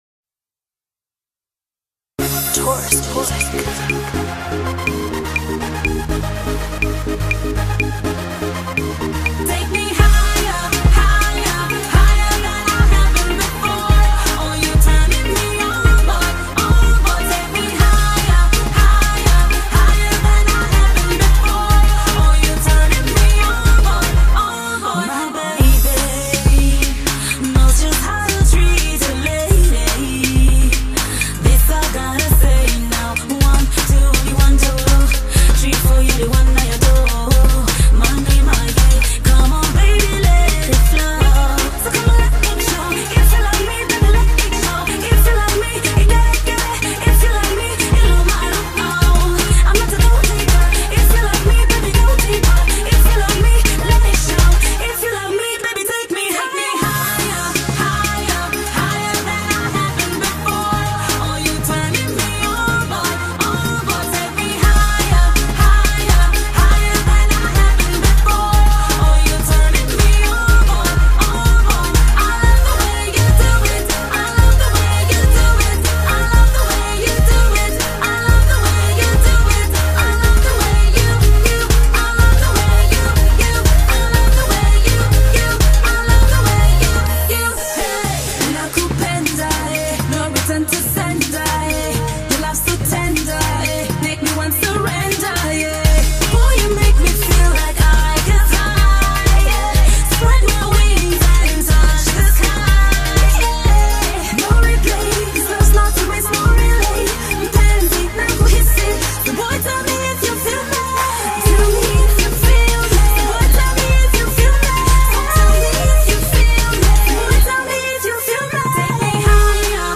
come together on this soon-to-be Afro Pop classic